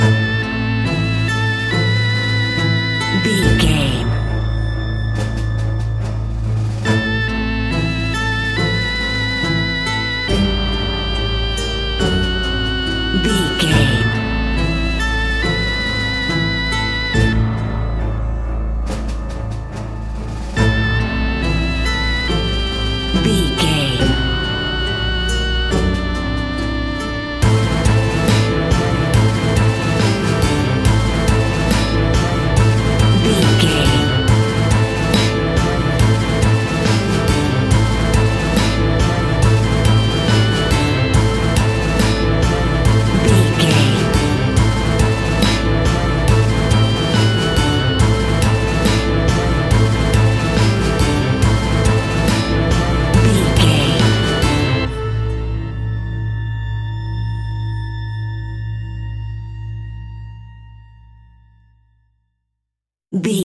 Aeolian/Minor
ominous
haunting
eerie
electric organ
strings
acoustic guitar
harp
synthesiser
drums
percussion
spooky
horror music